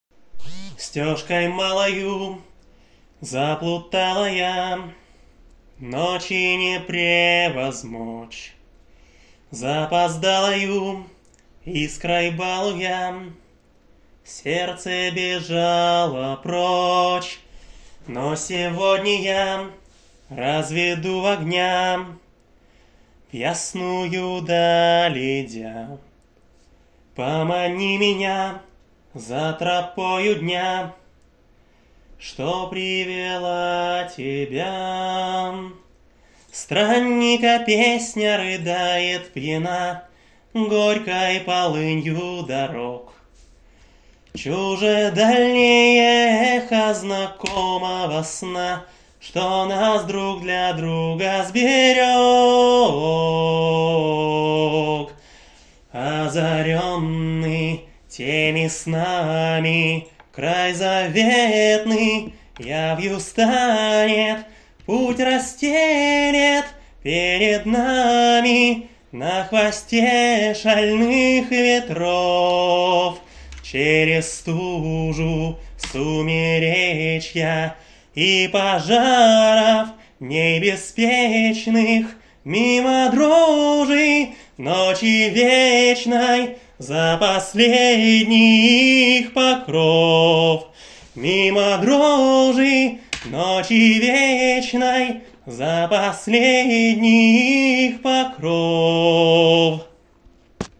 Исполняю традиционную доброчаньскую песню про лолисичку.
Ну откуда у меня инструменты.